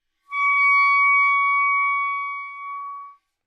单簧管单音 " 单簧管 C6
描述：在巴塞罗那Universitat Pompeu Fabra音乐技术集团的goodsounds.org项目的背景下录制。单音乐器声音的Goodsound数据集。 instrument :: clarinetnote :: Csharpoctave :: 6midi note :: 73microphone :: neumann U87tuning reference :: 442goodsoundsid :: 1521
标签： 纽曼-U87 单簧管 单注 多重采样 好声音 Csharp6
声道立体声